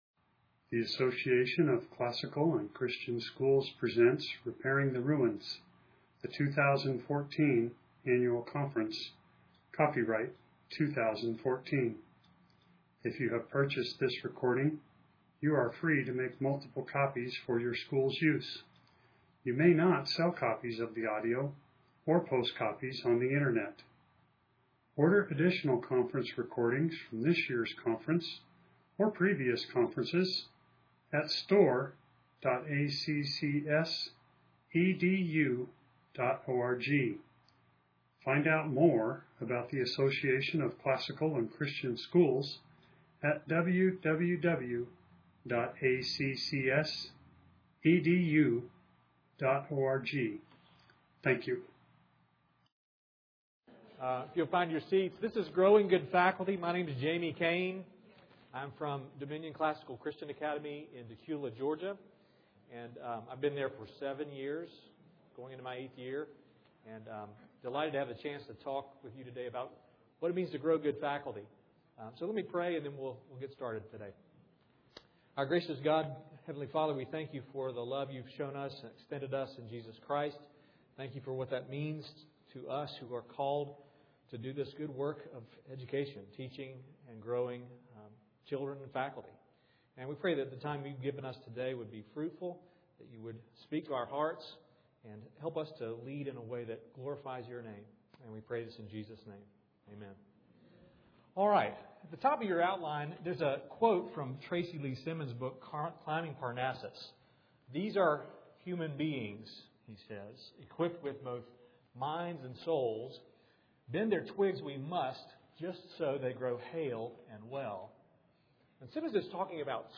2014 Leaders Day Talk | 0:48:08 | Leadership & Strategic, Training & Certification